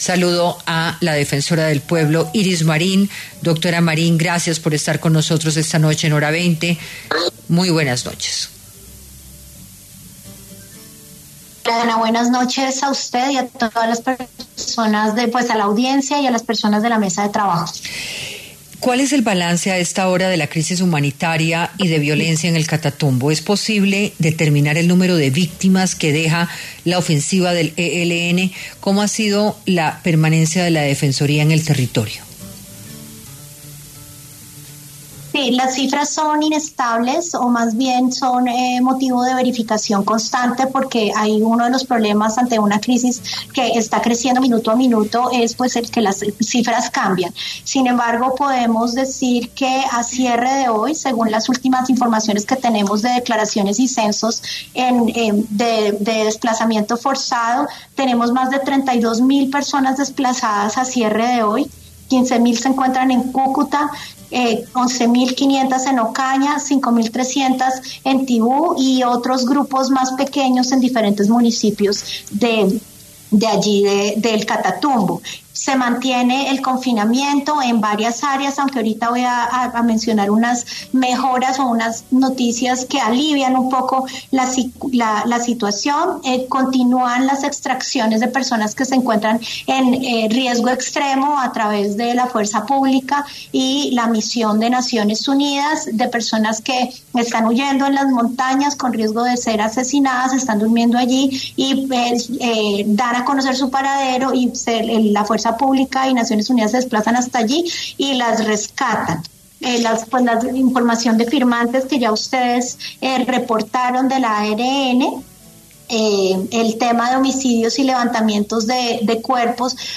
En diálogo con Hora 20 de Caracol Radio, Iris Marín, reveló que ya son 32.000 las personas desplazadas por la ola de violencia